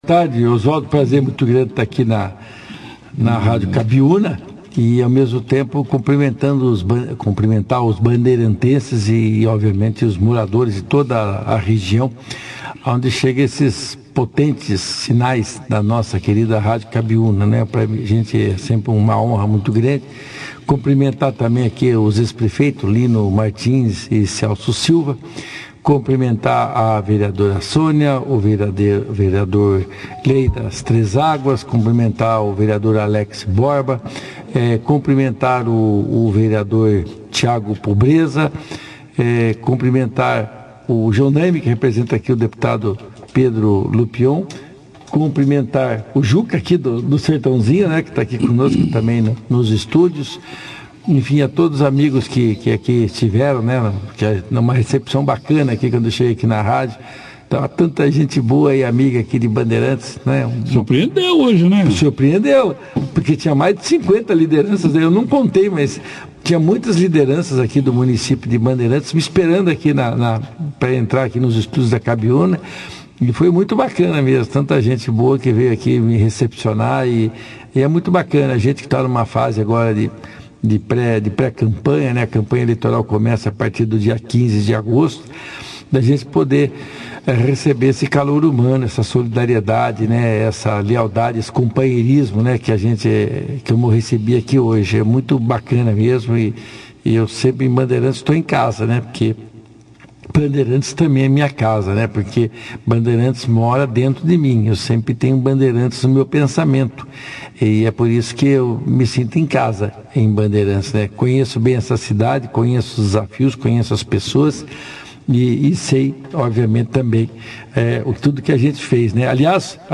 Deputado Romanelli, participou ao vivo do jornal Operação Cidade, falando sobre os trabalhos da semana na Assembleia Legislativa - Rádio Cabiuna
O deputado e primeiro Secretário da Assembleia Legislativa do Paraná, Luiz Claudio Romanelli, (foto), esteve em Bandeirantes na manhã desta sexta-feira, 05/08, onde participou ao vivo nos estúdios da Cabiúna Fm, da 2ª edição do jornal Operação Cidade.